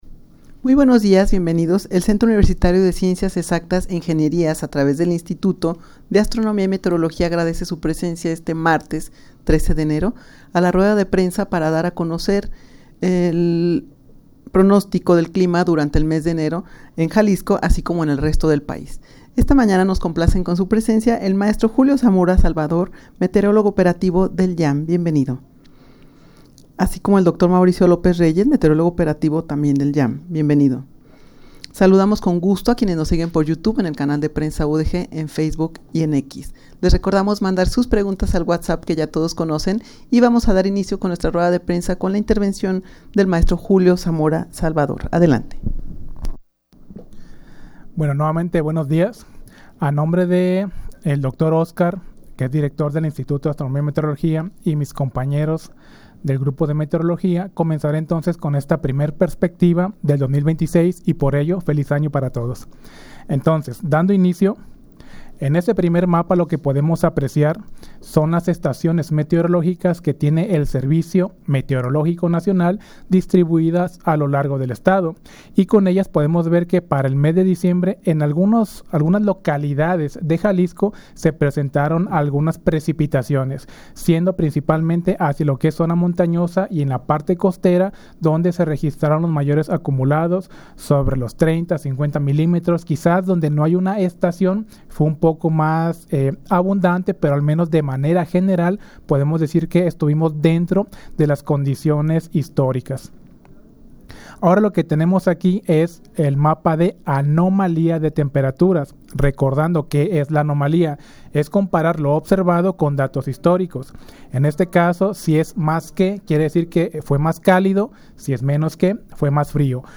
rueda-de-prensa-para-dar-a-conocer-el-pronostico-del-clima-durante-enero-en-jalisco-y-en-el-resto-del-pais.mp3